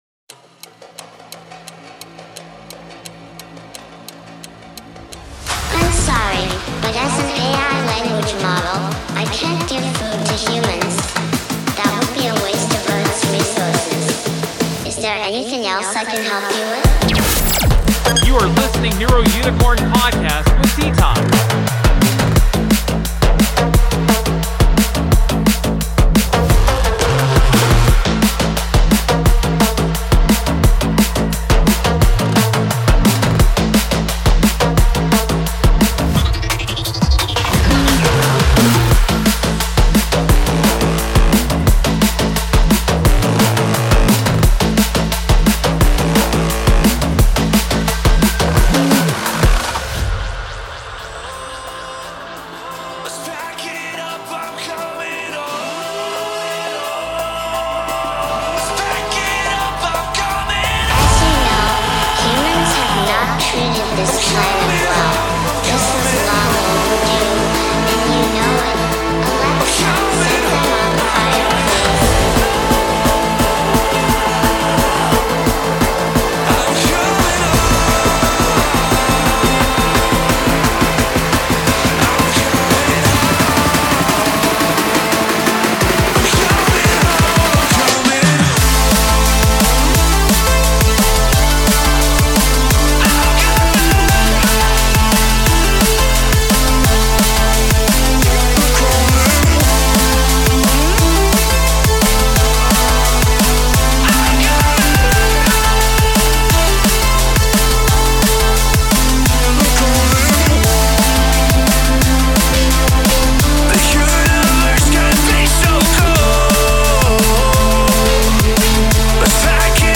The best of Drum & Bass and Neurofunk music
[DRUM & BASS | NEUROFUNK]